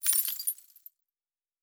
Objects Small 04.wav